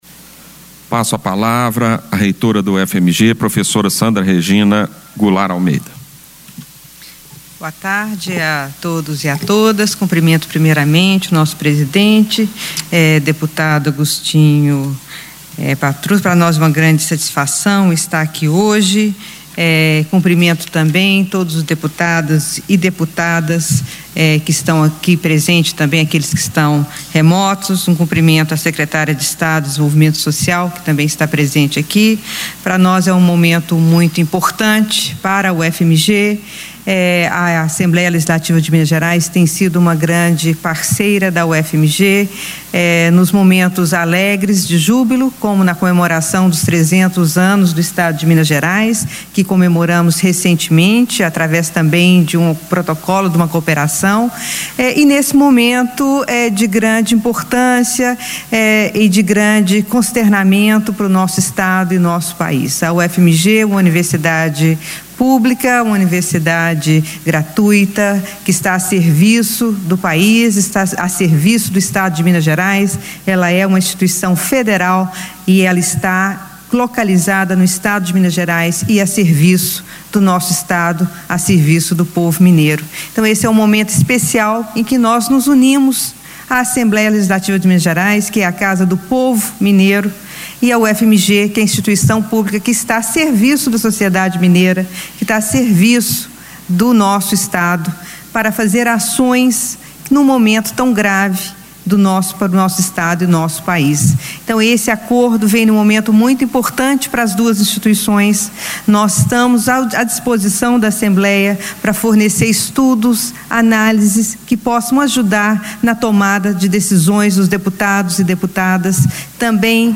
Discursos e Palestras
O pronunciamento durante solenidade no Plenário, para assinatura de protocolo de intenções para a cooperação técnica entre a UFMG e a ALMG, reforça a importância de parcerias neste cenário atual de pandemia pela Covid-19.